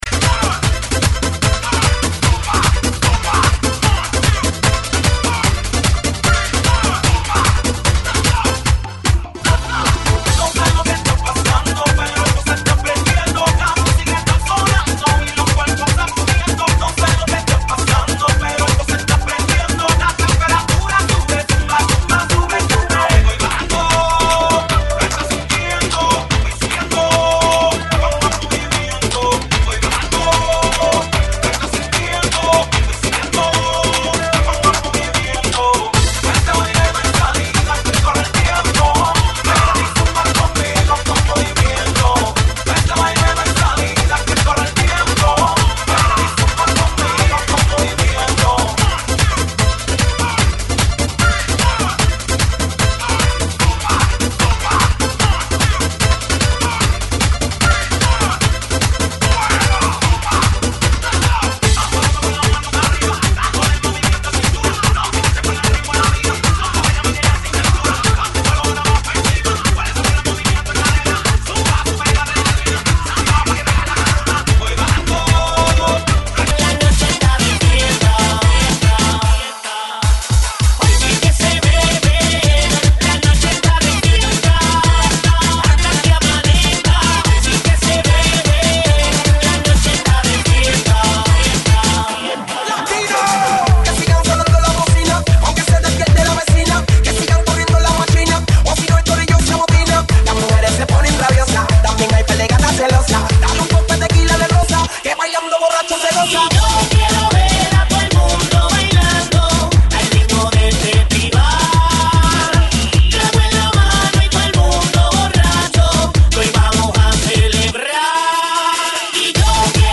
GENERO: LATINO – REGGAETON